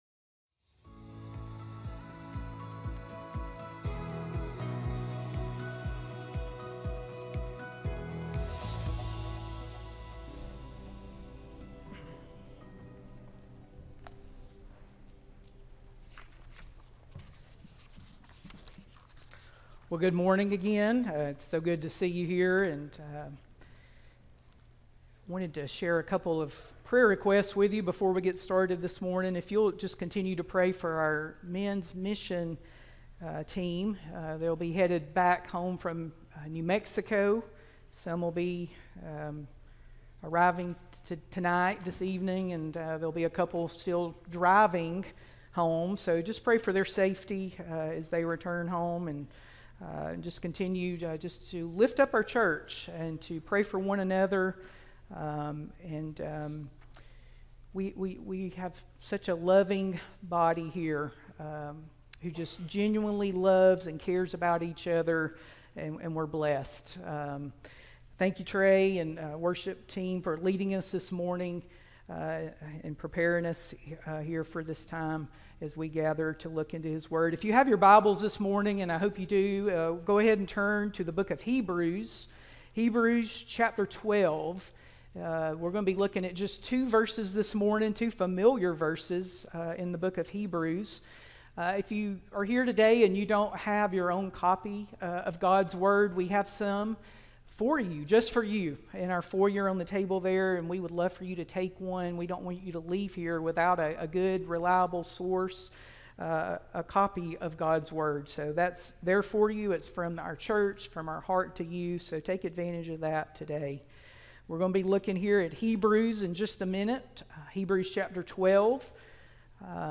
Sermon-5-18-25-audio-from-video.mp3